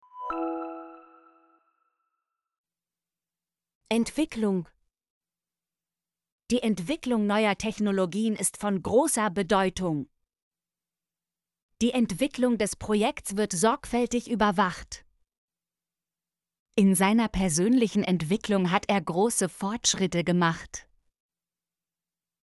entwicklung - Example Sentences & Pronunciation, German Frequency List